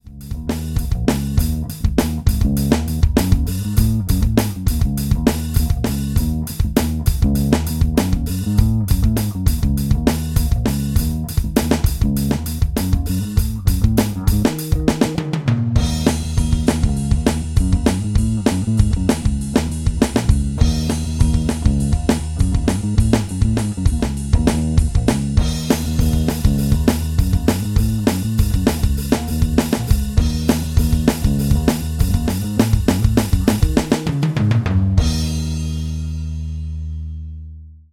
Själv använder jag en Alesis DM5, som har tolv trigger-ingångar och en ingång för hi-hat-pedalen.
När en nybörjartrummis som jag dunkar på padsen till en monoton basgång kan det låta så här (i en sammanhängande, okvantiserad och oförskönad tagning):
drum_pad_demo.mp3